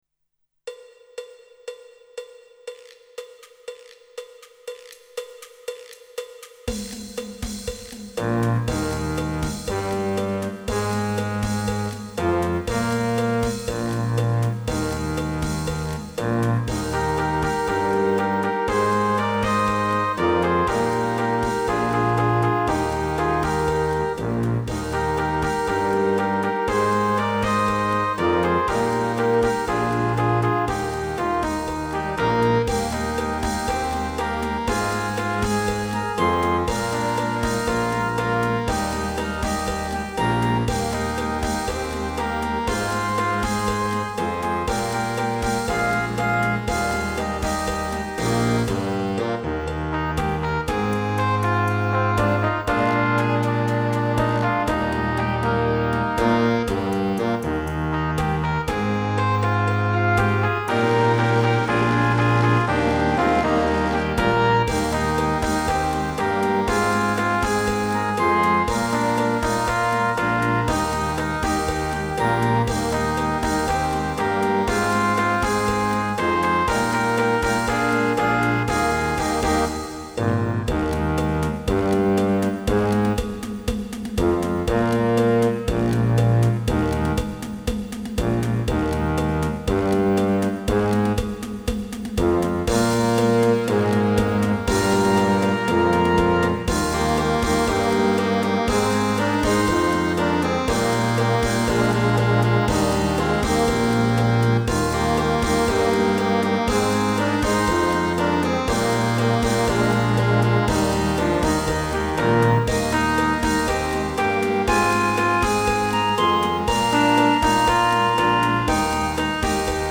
◎　デモ音源（ＭＰ３ファイル）
さらばジャマイカ　　　　　(デジタル音源)